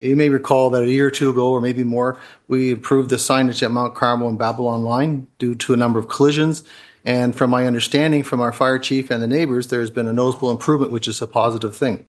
That’s due to a call for action to enhance signage and reduce accidents during Huron County Council’s meeting last Wednesday (March 6).
Mayor of South Huron George Finch initiated the conversation by highlighting the positive impact of previous signage improvements.
march-11-hc-council-meeting-stop-signs-finch-1.mp3